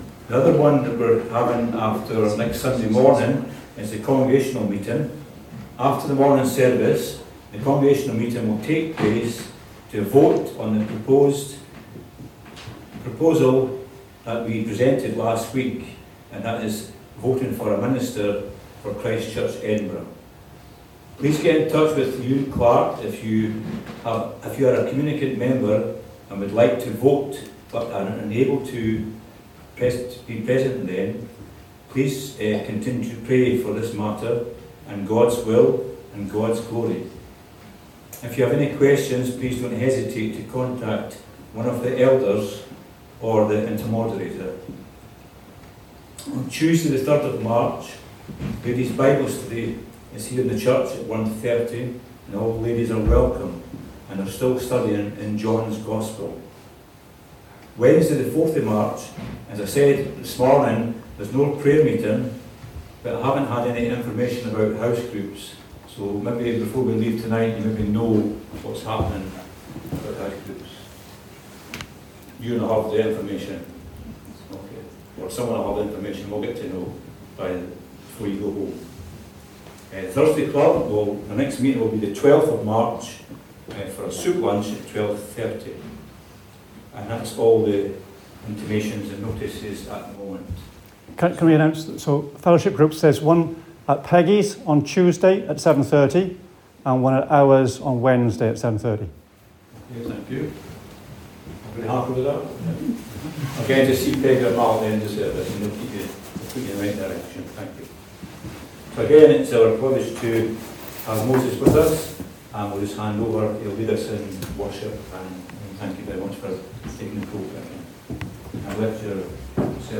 An audio recording of the 6:00pm service.